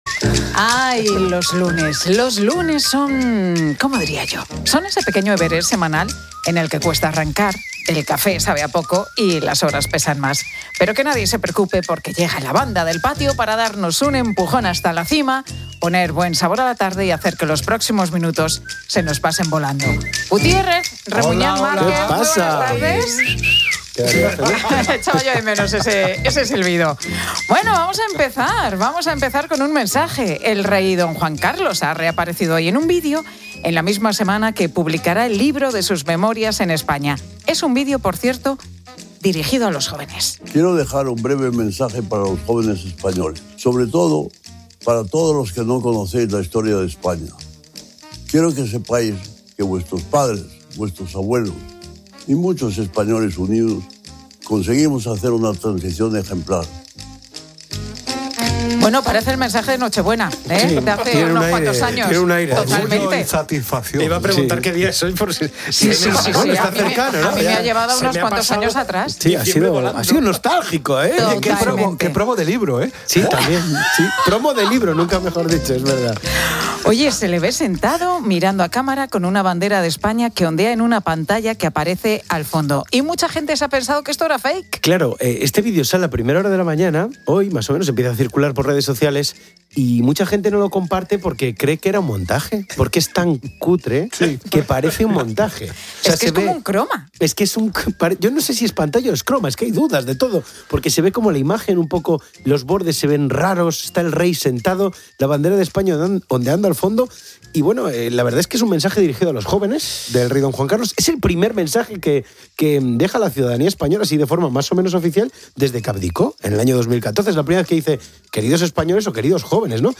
Pilar García Muñiz y la banda del patio analizan el vídeo del Rey Juan Carlos a los jóvenes de España